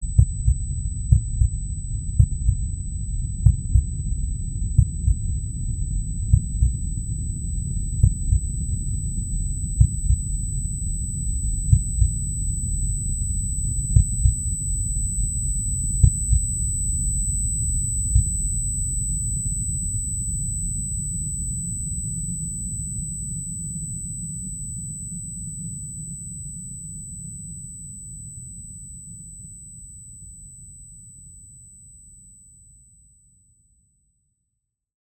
Последние удары сердца угасающего человека